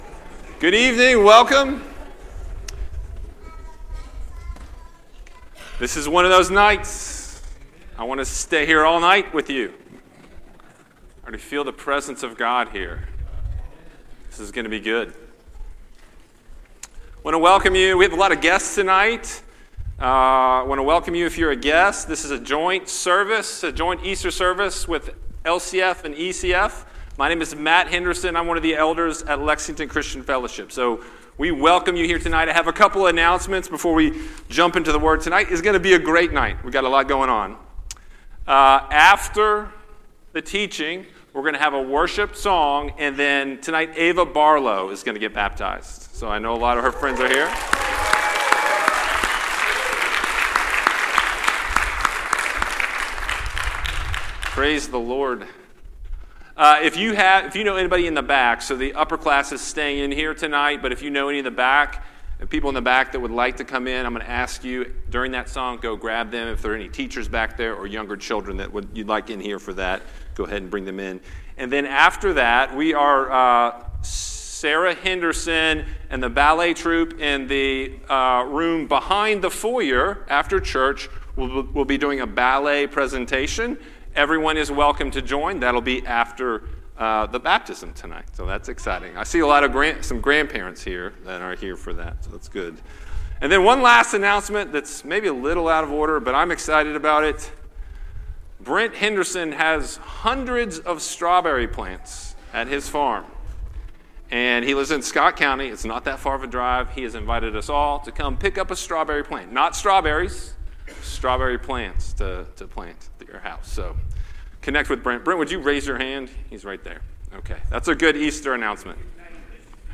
Weekly Sermons from Lexington Christian Fellowship